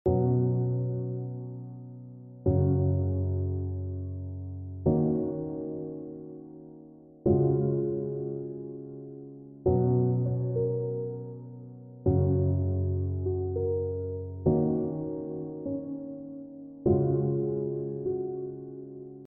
Download Soft Piano sound effect for free.
Soft Piano